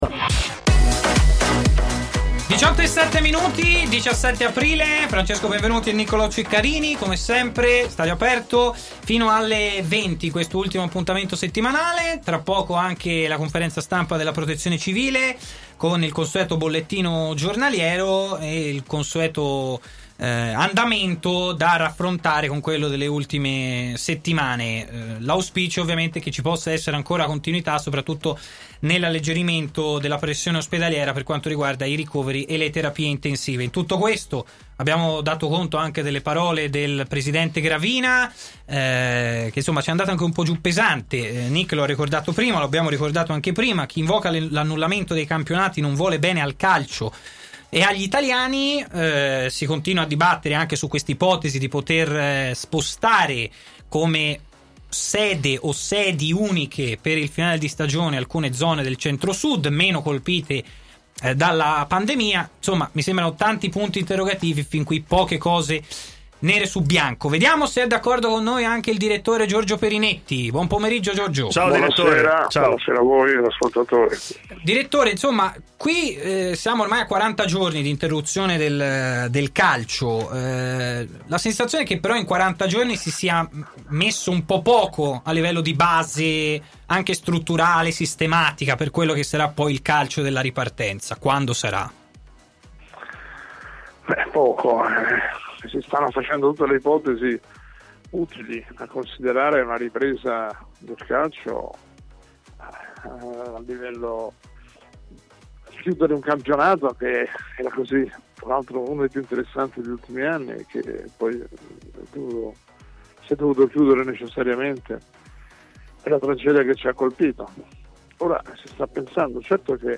intervenuto in diretta nel corso di Stadio Aperto, sulle frequenze web di TMW Radio